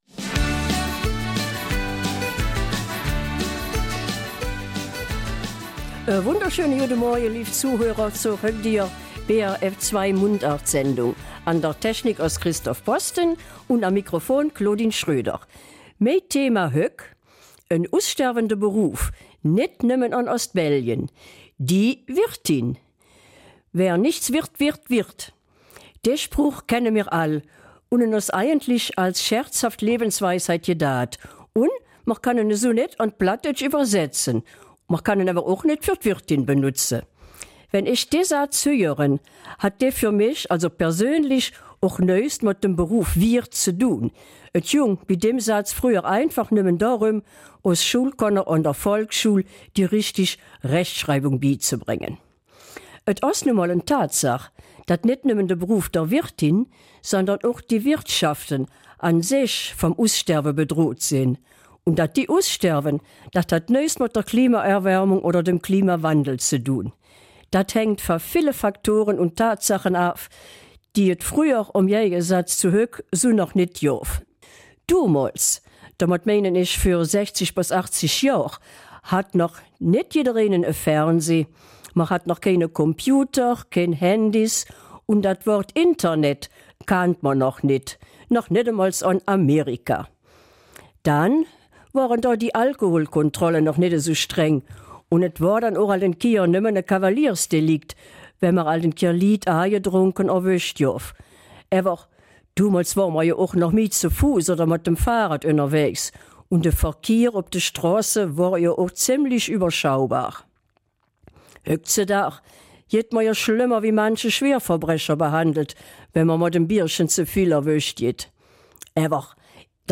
Eifeler Mundart - 22. Juni